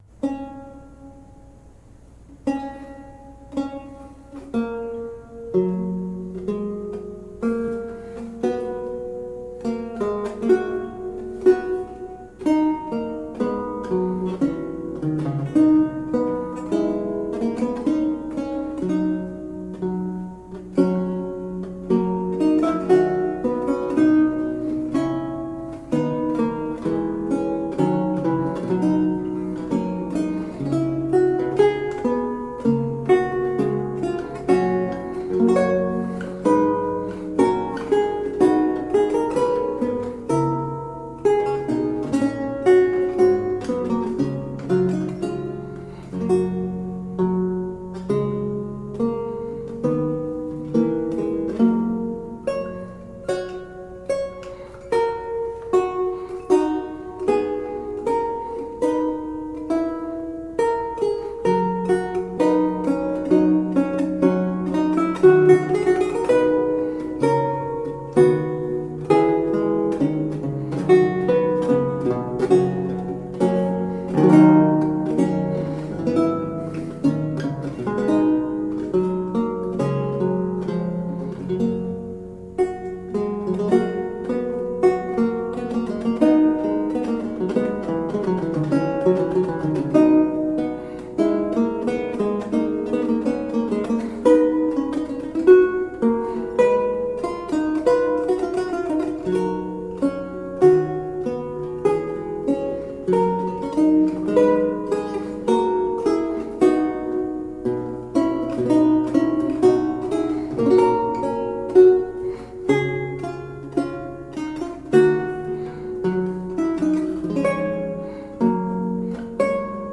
Lute music of 17th century france and italy
Classical, Baroque, Renaissance, Instrumental
Lute